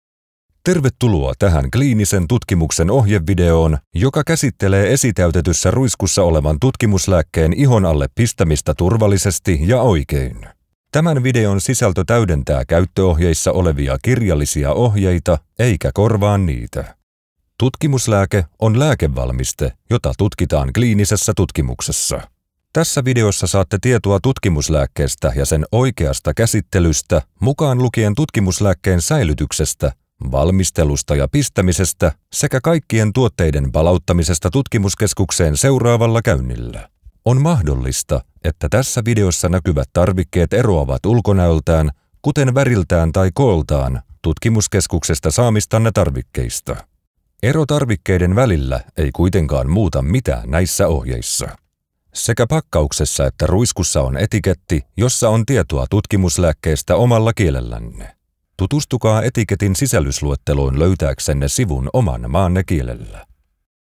Male
Adult (30-50)
I have a deep, persuasive and memorable voice.
Medical Narrations
All Kinds Of Medical Narration